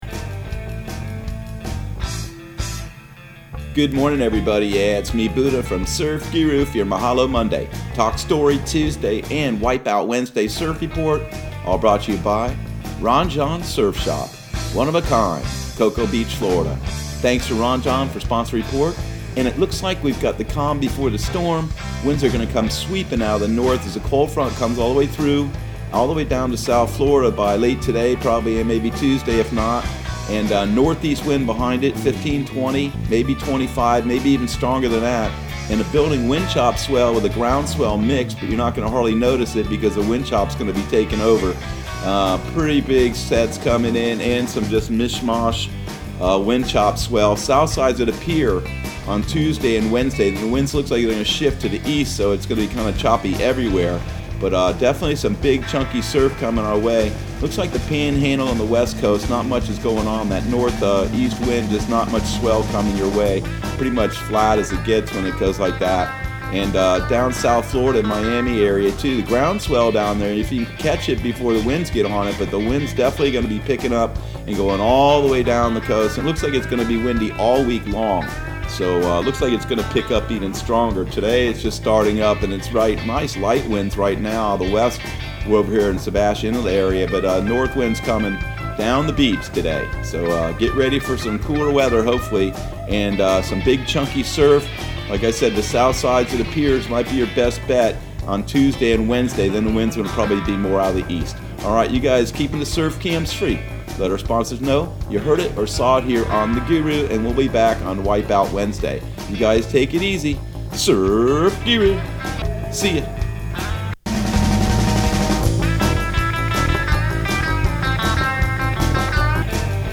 Surf Guru Surf Report and Forecast 11/16/2020 Audio surf report and surf forecast on November 16 for Central Florida and the Southeast.